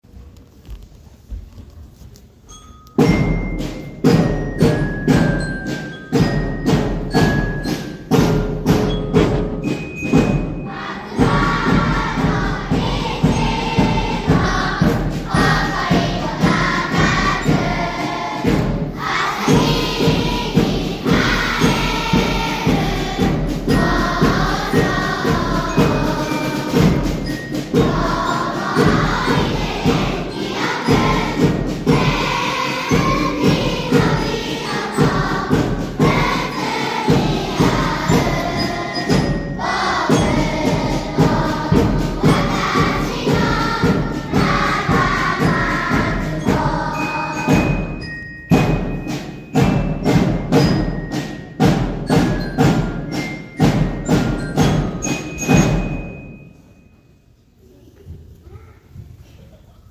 0531全校朝会での♪校歌♪
6月全校朝会校歌.mp3